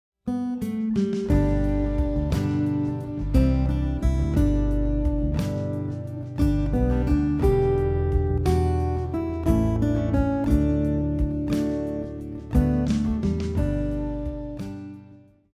Blessed Assurance is a popular Christian hymn composed by Phoebe Palmer Knapp and written by Fanny Crosby.
Chords for Blessed Assurance in G Major
Backing Track
Blessed Assurance – melody preview
Blessed-Assurance-melody-preview.mp3